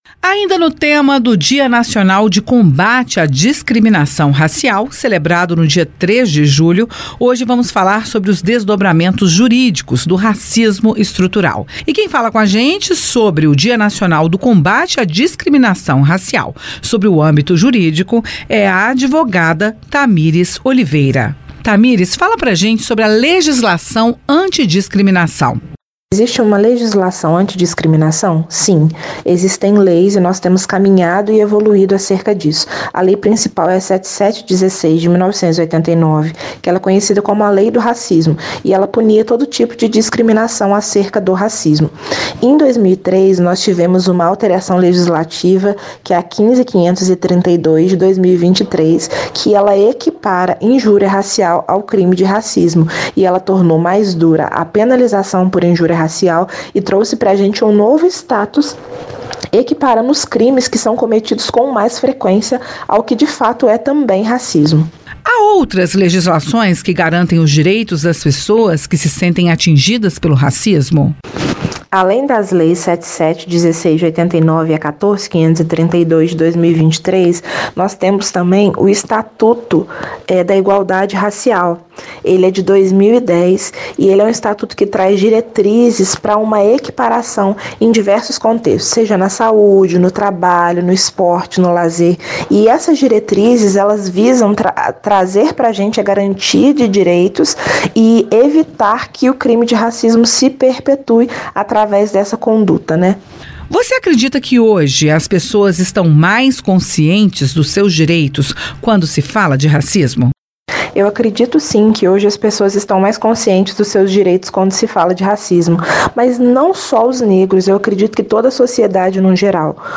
Racismo estrutural: advogada analisa impactos jurídicos e psicológicos